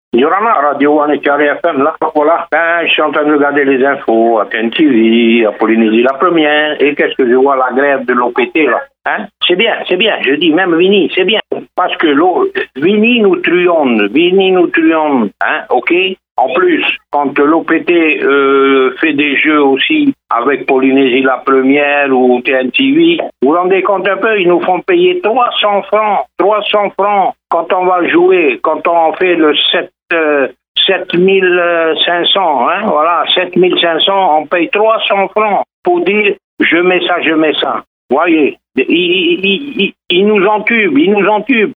Répondeur de 6:30, le 18/02/2021